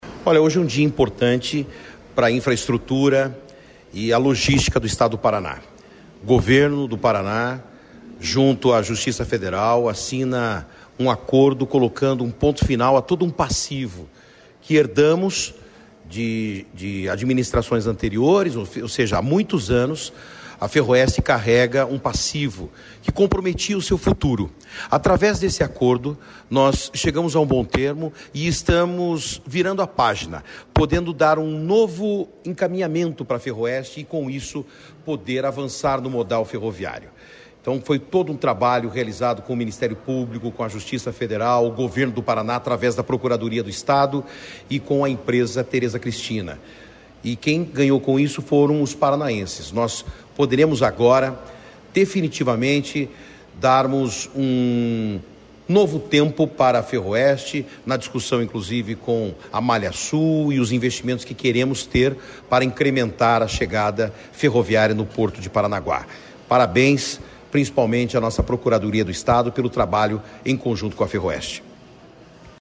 Sonora do secretário Estadual da Infraestrutura e Logística, Sandro Alex, sobre o acordo judicial que encerra passivo da Ferroeste